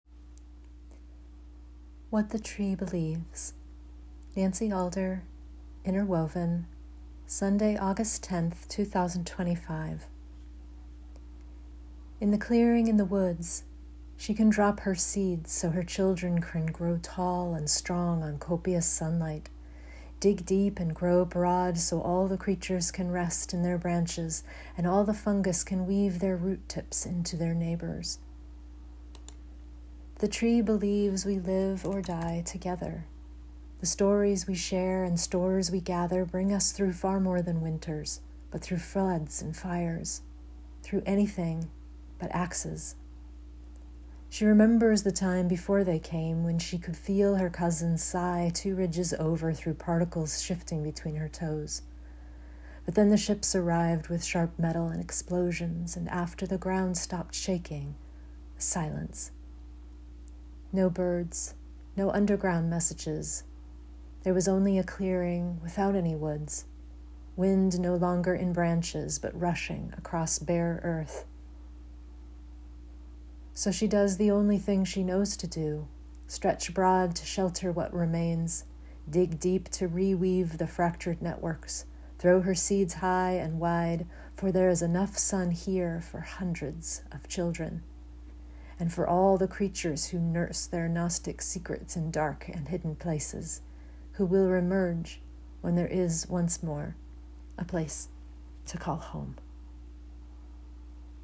Enjoy this 2-minute poem or let me read it to you here